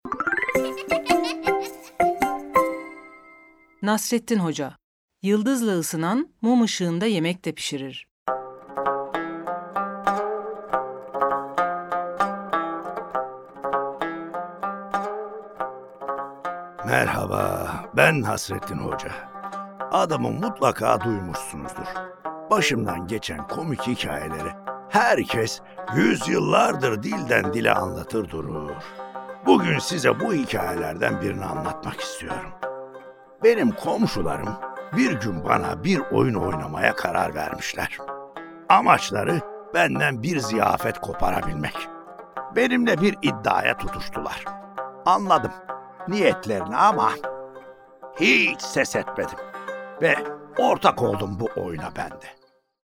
Nasreddin Hoca: Yıldızla Isınan, Mum Işığında Yemek de Pişirir Tiyatrosu